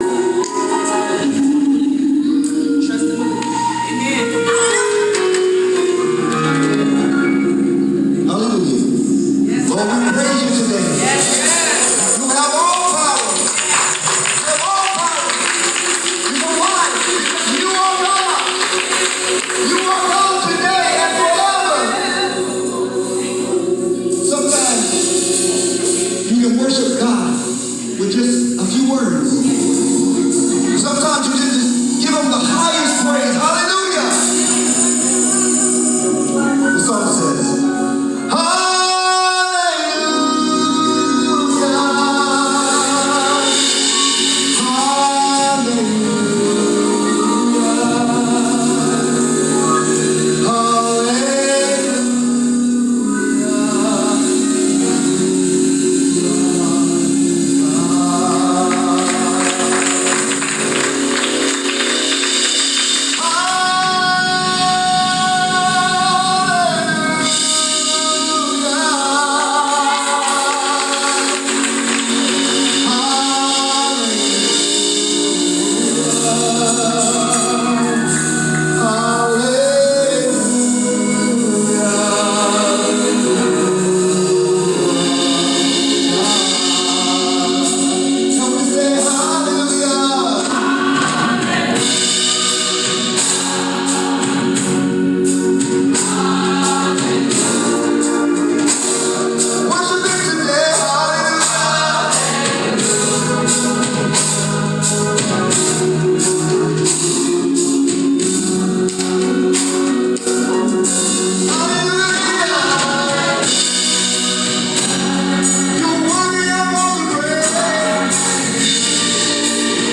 Congregational Choir